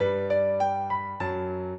piano
minuet1-7.wav